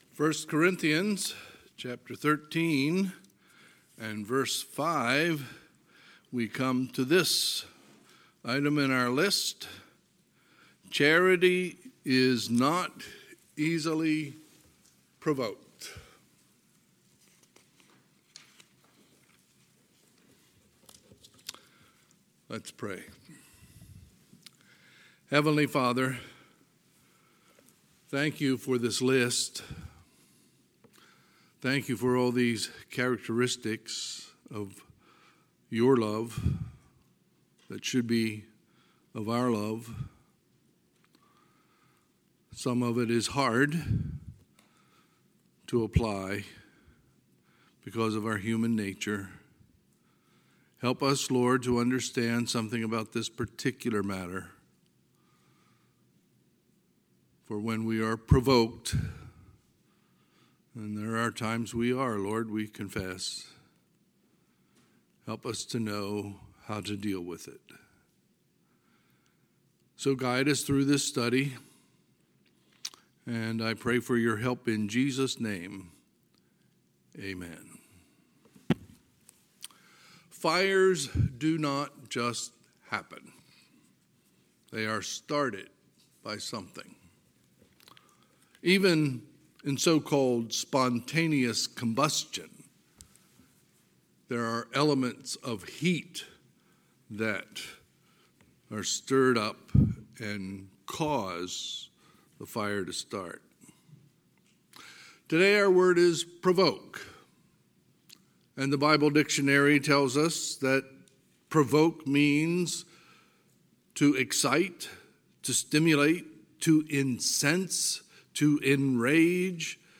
Sunday, August 29, 2021 – Sunday AM
Sermons